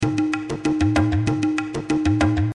Tag: 96 bpm Ethnic Loops Tabla Loops 433.94 KB wav Key : Unknown